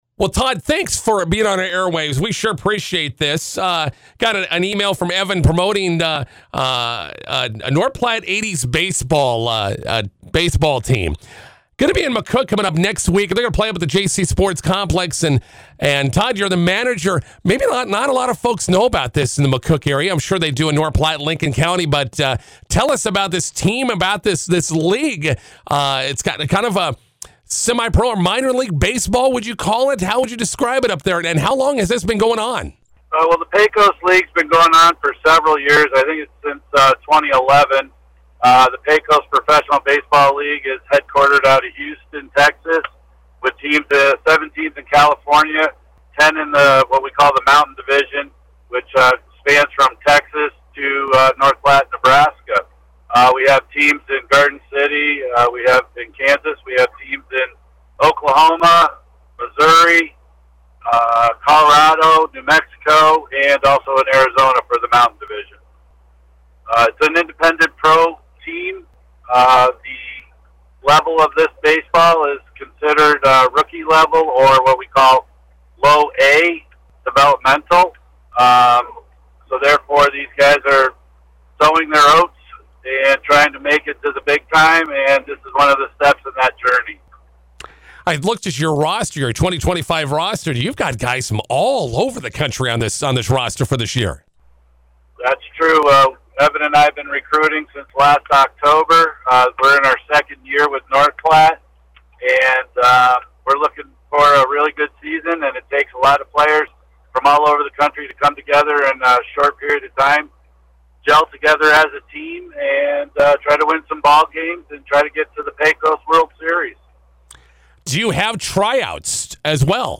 INTERVIEW: North Platte 80s Baseball coming to McCook on Tuesday.